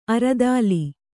♪ aradāli